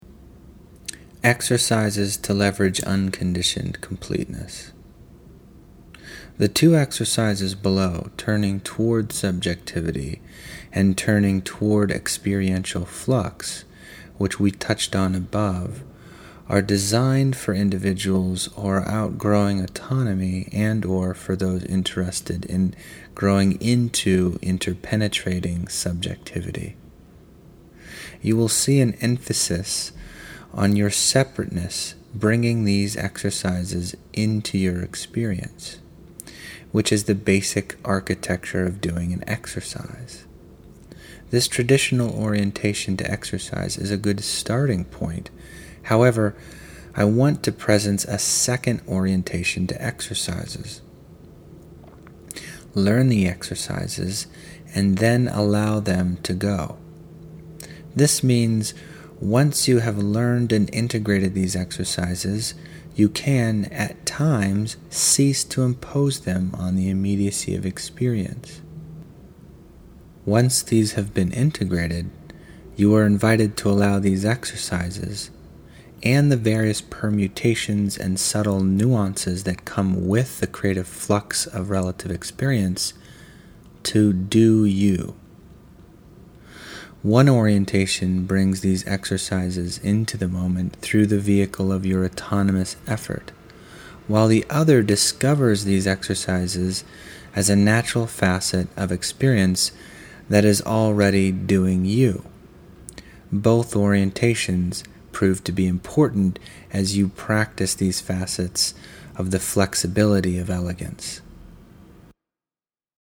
Narrated exercises from Chapter Seven, The Paradox of Completeness: